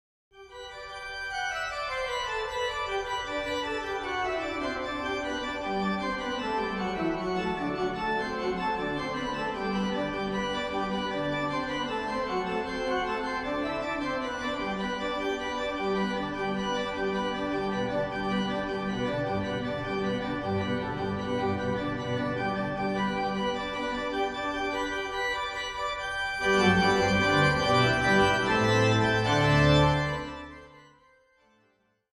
Description:   This is a collection of organ transcription.